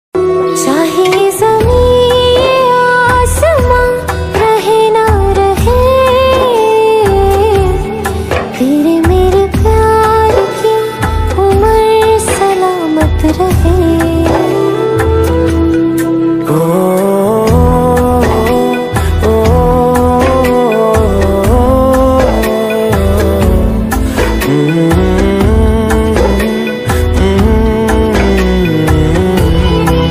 sad ringtone